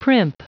Prononciation du mot primp en anglais (fichier audio)
Prononciation du mot : primp